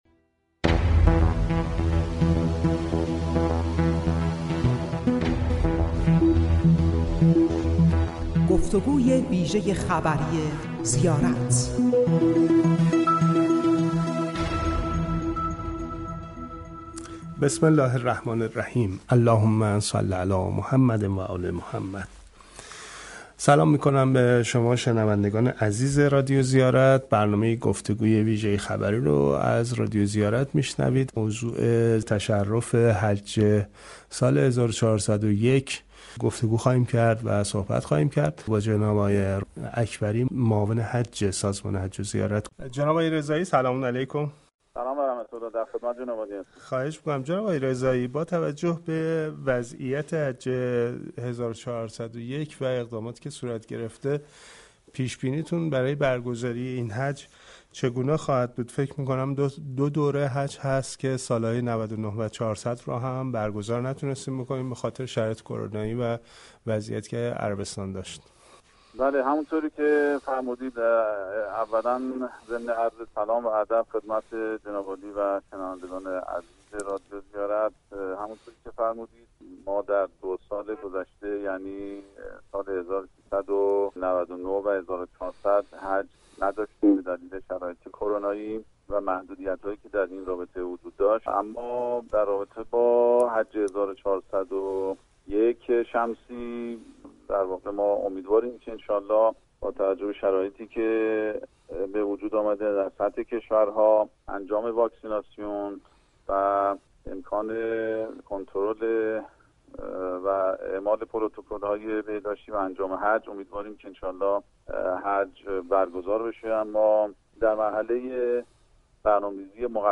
گفتگوی ویژه خبری رادیو زیارت